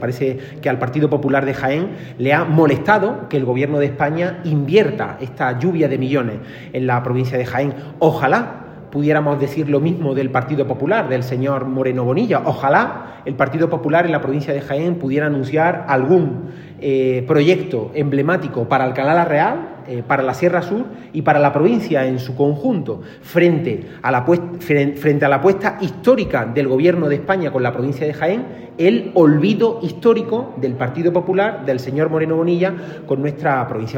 Reunión del Grupo Socialista de la Diputación en Alcalá la Real
Cortes de sonido